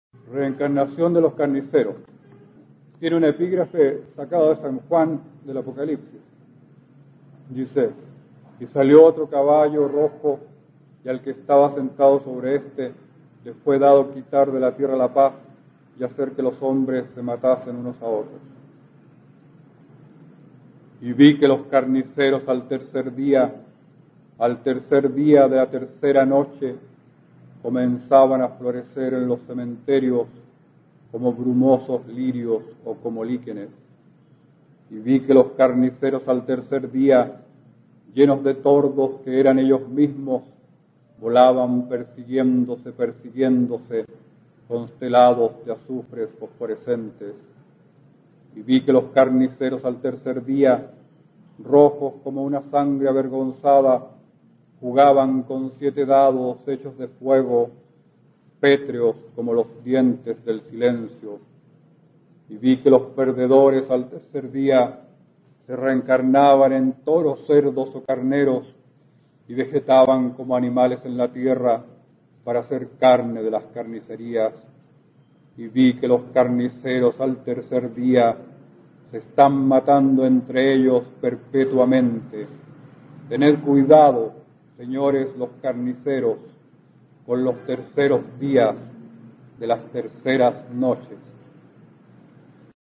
Aquí puedes escuchar al poeta chileno Óscar Hahn, perteneciente a la Generación del 60, recitando su poema Reencarnación de los carniceros, del libro "Esta rosa negra" (1961), obra con la que ganó el Premio Alerce de la Sociedad de Escritores.
Poema